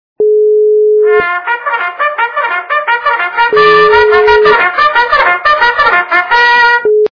При прослушивании Горн - Труба зовет качество понижено и присутствуют гудки.
Звук Горн - Труба зовет